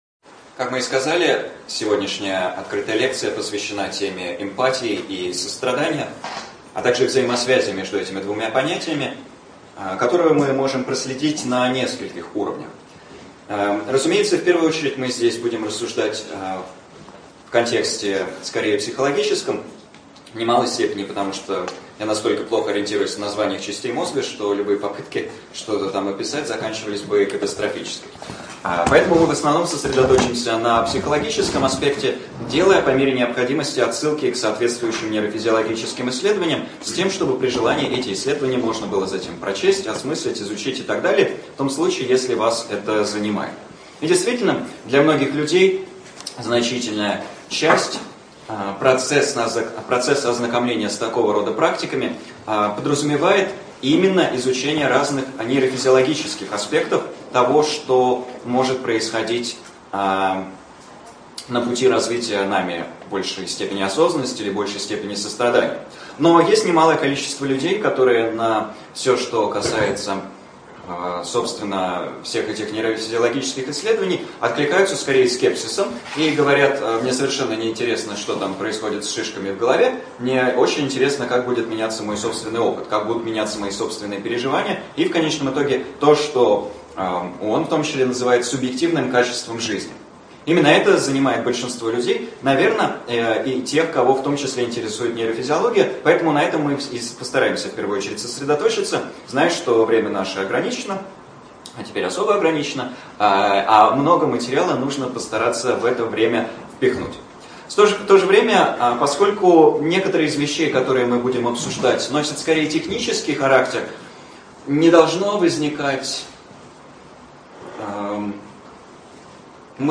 ЧитаетАвтор